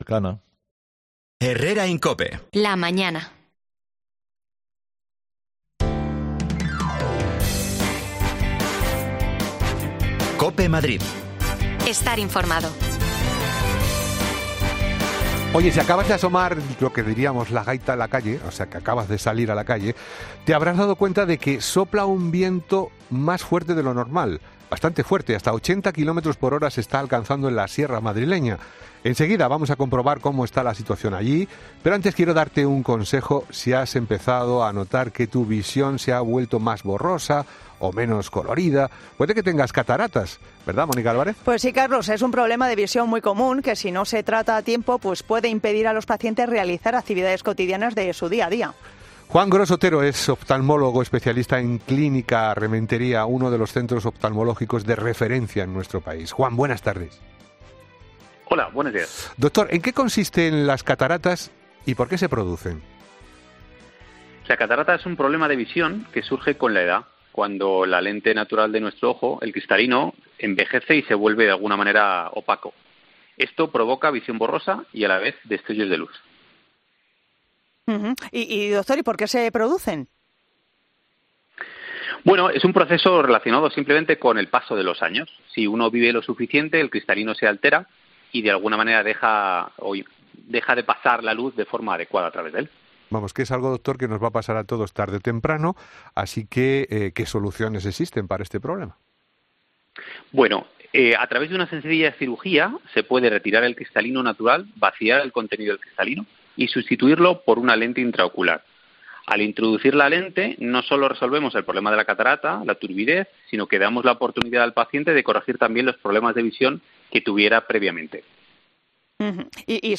AUDIO: Alerta amarilla por viento en la región pero especialmente en la sierra. Nos acercamos a un hotel en Cercedilla para comprobarlo...
Las desconexiones locales de Madrid son espacios de 10 minutos de duración que se emiten en COPE , de lunes a viernes.